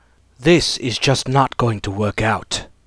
vs_fScarabx_dyin.wav